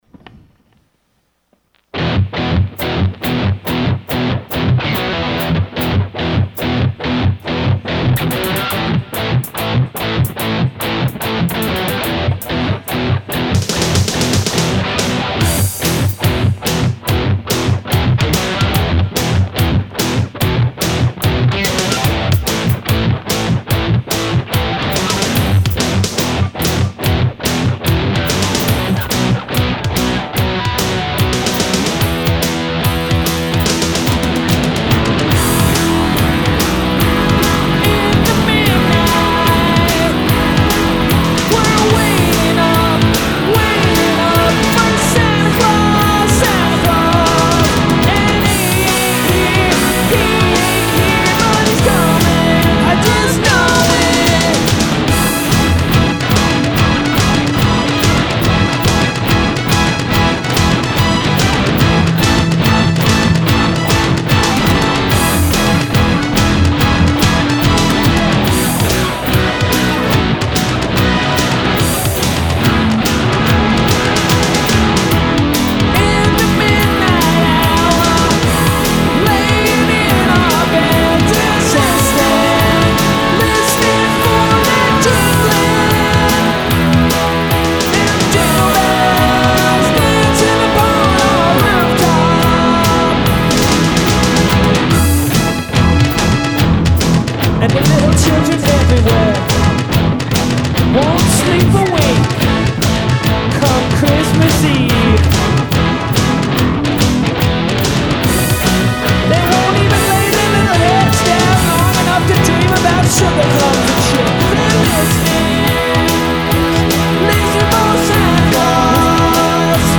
and the song sounds new and shiny!